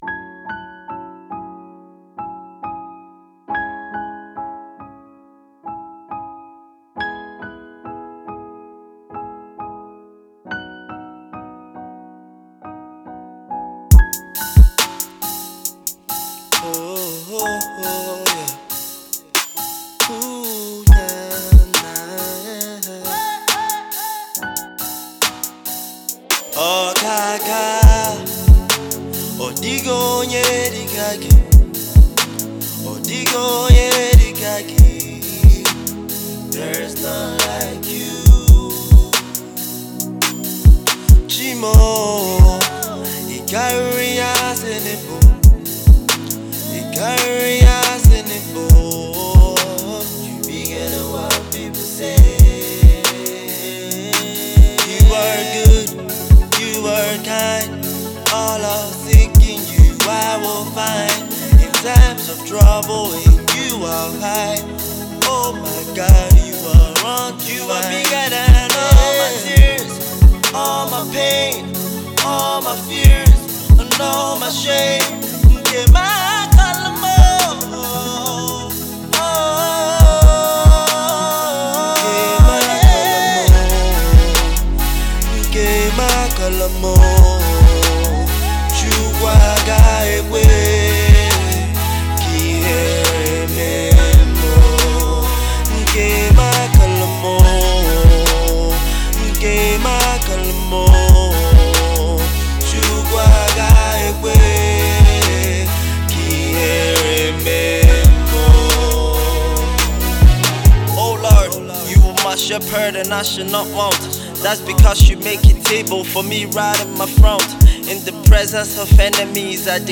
Delivering a soul-stirring and heart-warming sound